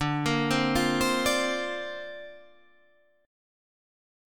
Dm7#5 chord